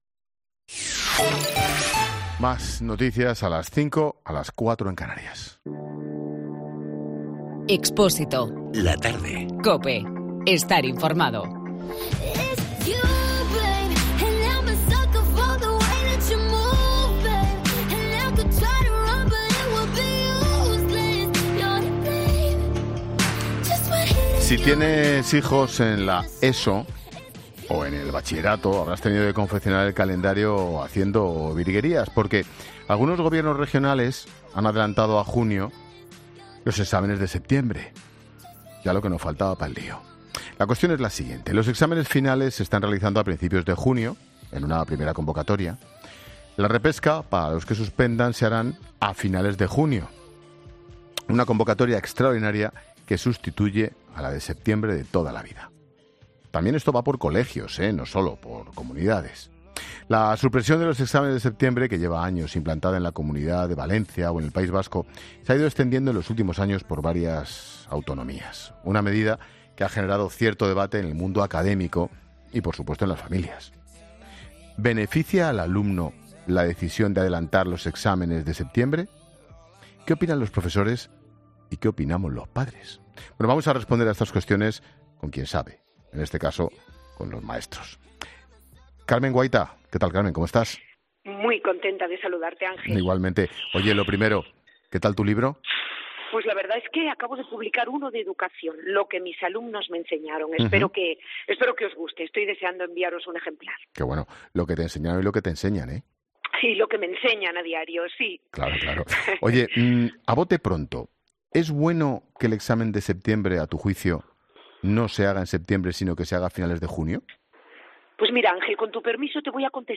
En 'La Tarde' hemos hablado con dos expertos para analizar esta cuestión